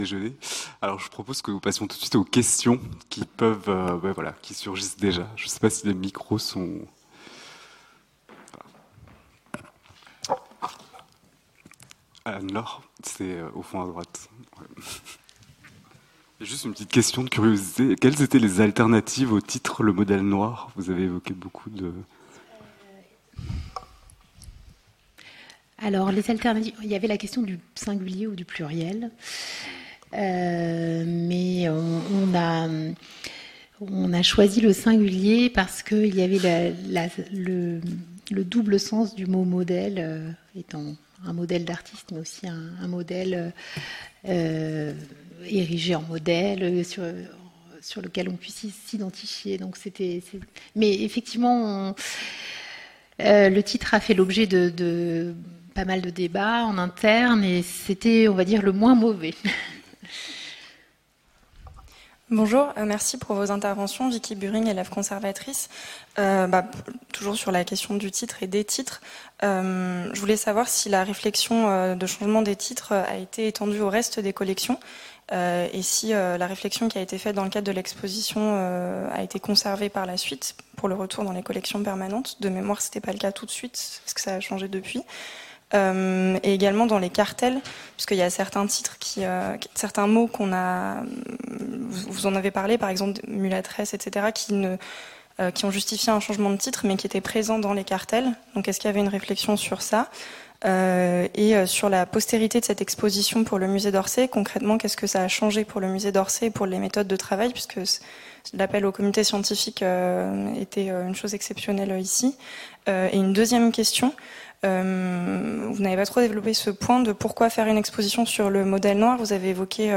Discussion - Une appropriation culturelle des communs | Canal U